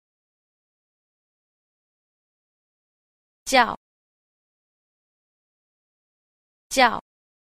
8. 叫 – jiào – khiếu (gọi là)